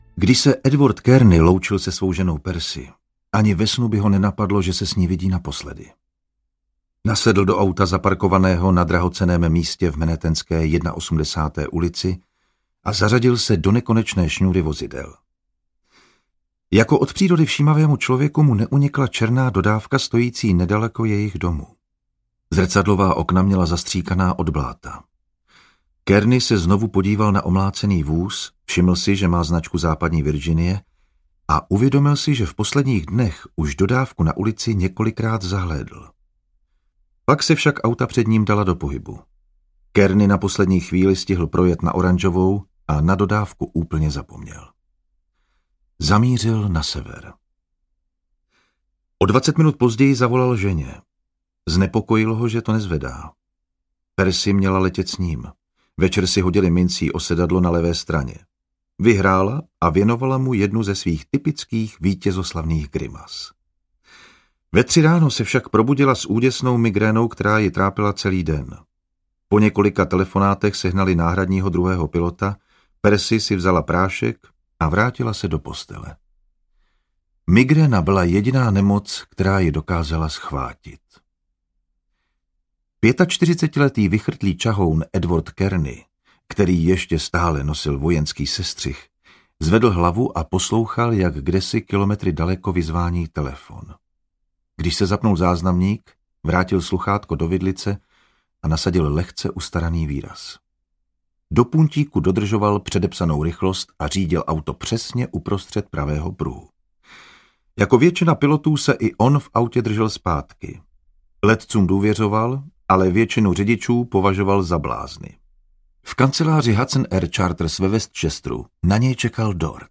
Tanečník audiokniha
Ukázka z knihy
• InterpretJan Vondráček